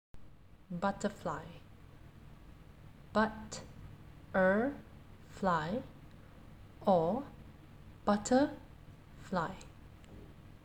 4. Butterfly – ‘butt’ ‘er’ ‘fly or ‘butter’ ‘fly’ (